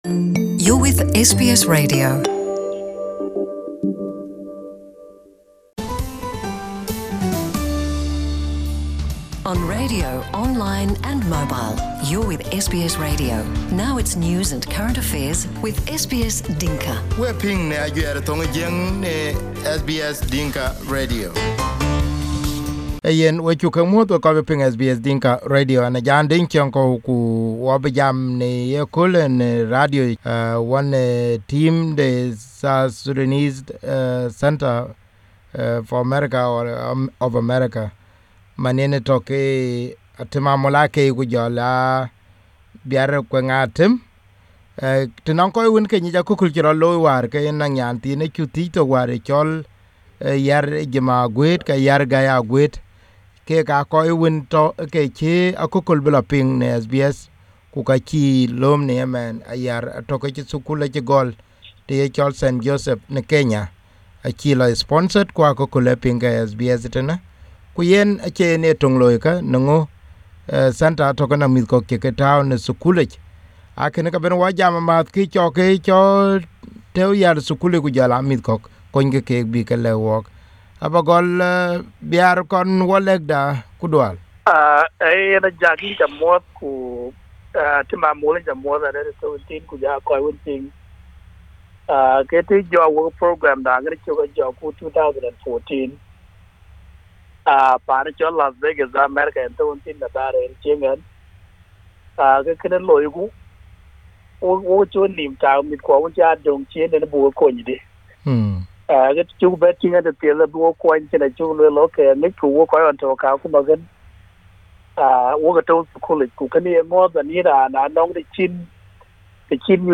SBS Dinka